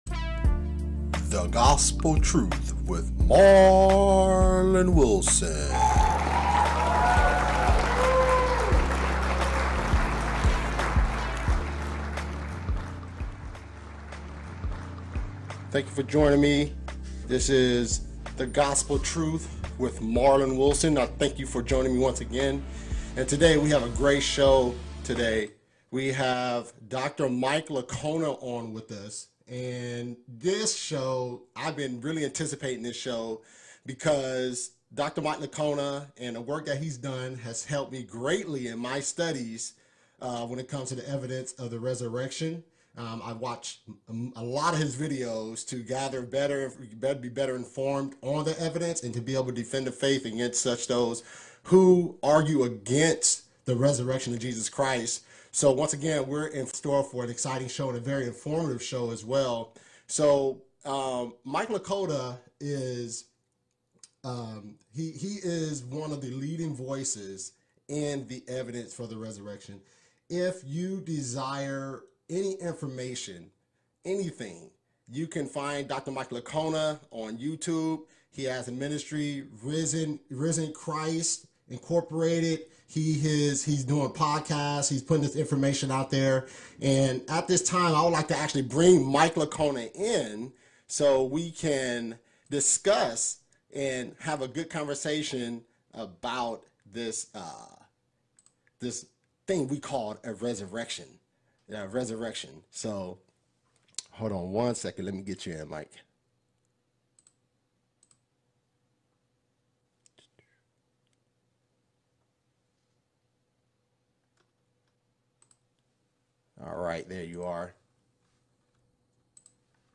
Interview: Evidence for the Ressurection